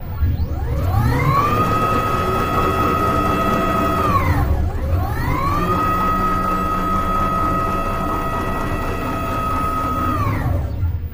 Long Machine Motor Like Samples From Motion Control